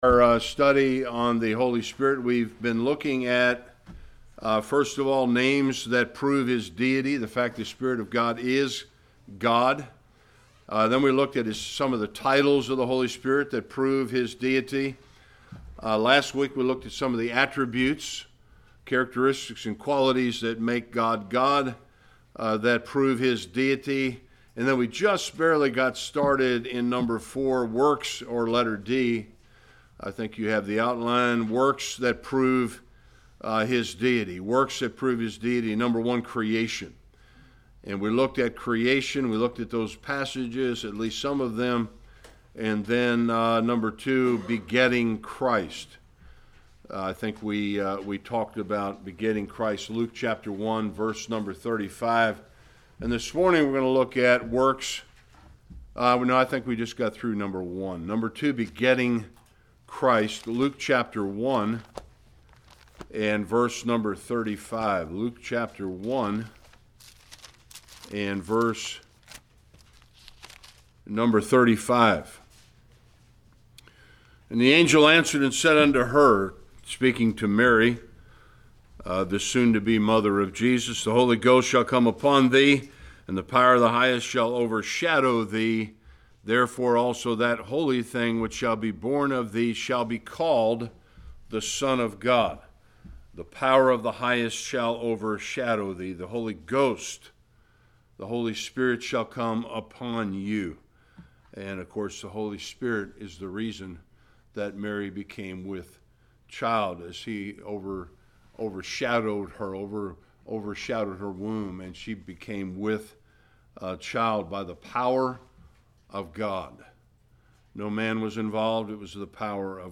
Various Passages Service Type: Sunday School A continuation of the proofs that the Holy Spirit is God.